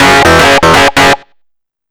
RIFFSYNT03-L.wav